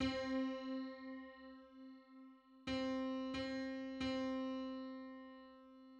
Licensing [ edit ] Public domain Public domain false false This media depicts a musical interval outside of a specific musical context.